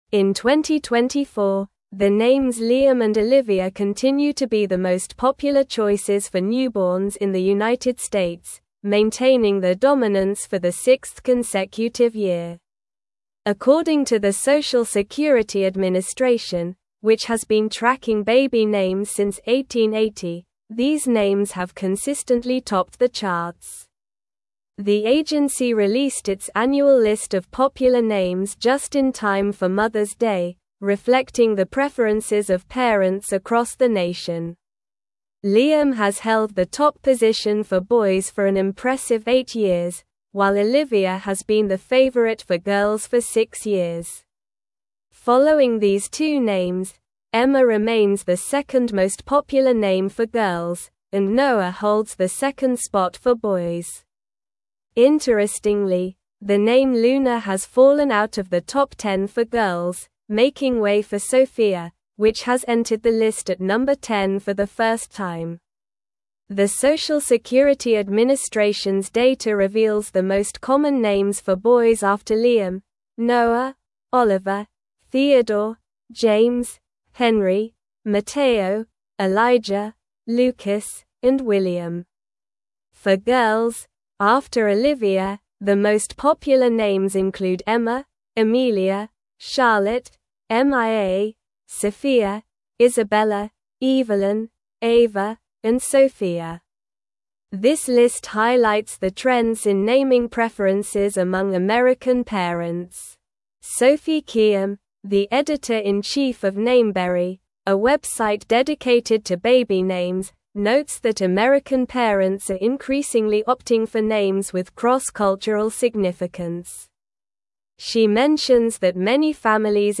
Slow
English-Newsroom-Advanced-SLOW-Reading-Liam-and-Olivia-Remain-Top-Baby-Names-for-2024.mp3